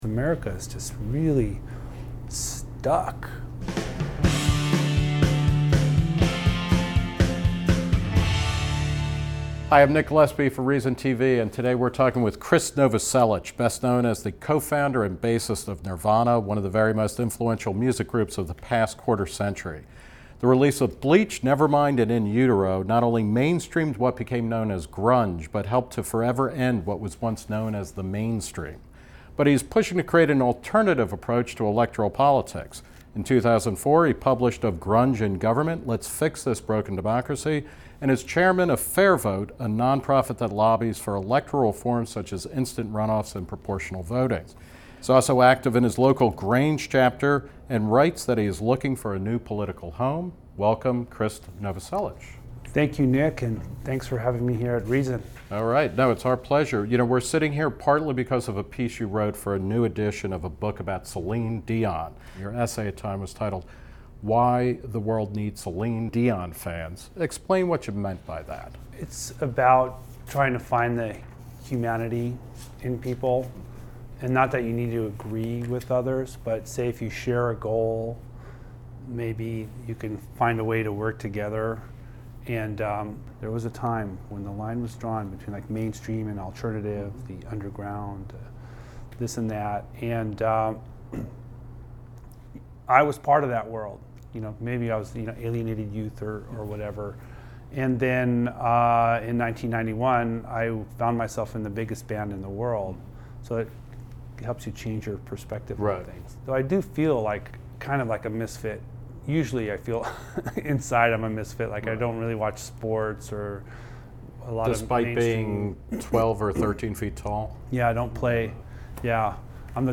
The Reason Interview